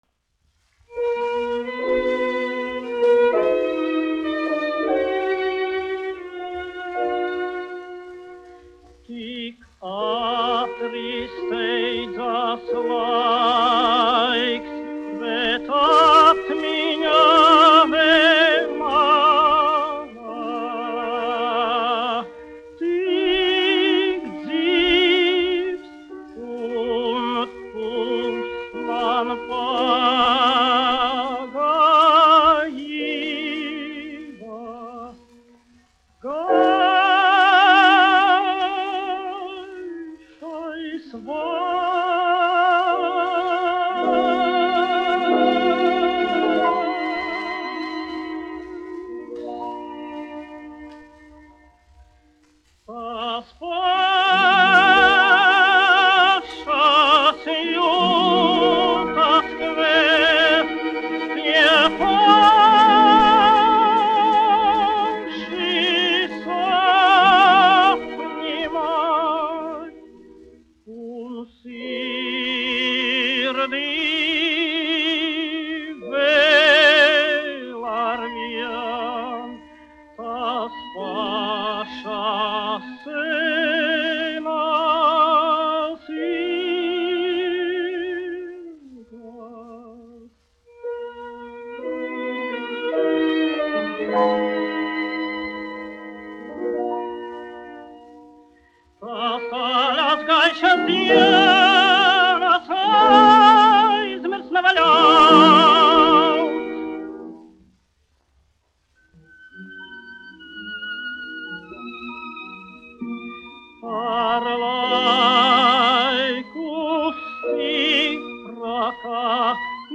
1 skpl. : analogs, 78 apgr/min, mono ; 25 cm
Romances (mūzika)
Skaņuplate
Latvijas vēsturiskie šellaka skaņuplašu ieraksti (Kolekcija)